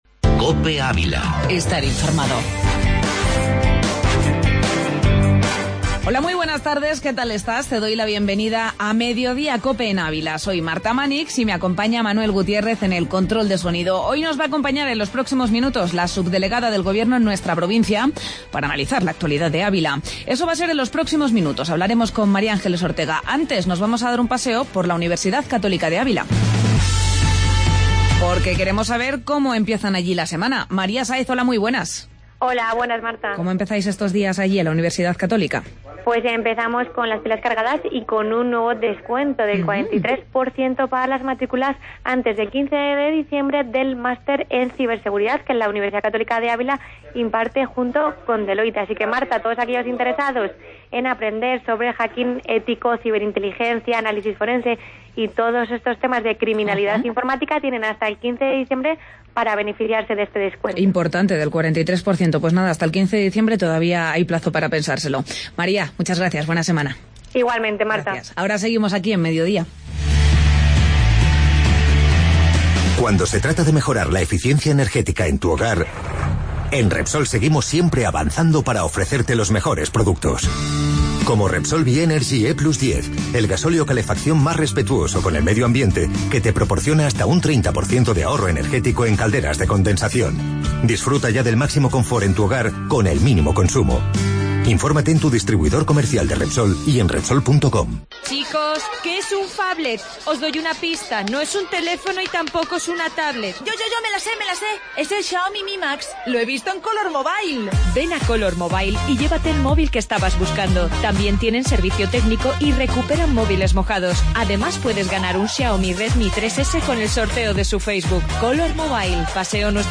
AUDIO: Entrevista Subdelegada del Gobierno en Ávila